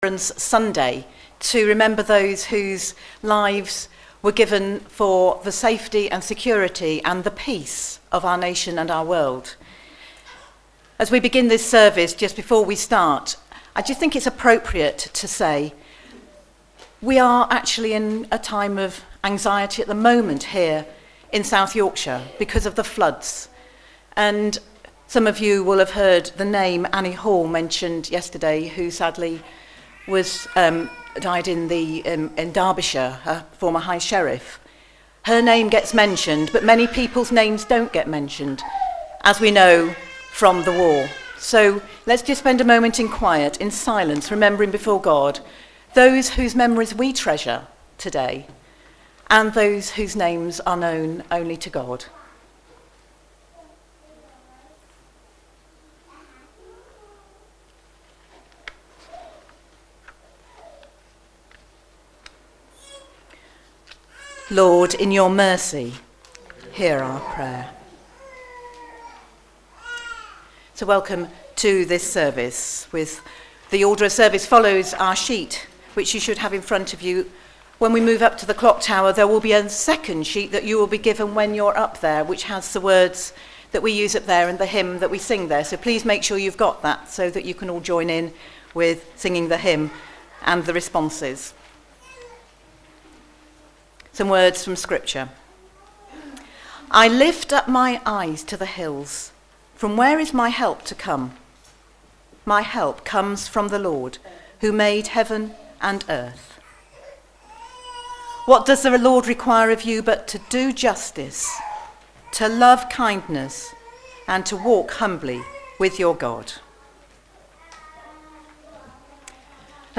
Remembrance Services.